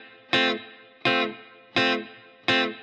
DD_TeleChop_85-Fmaj.wav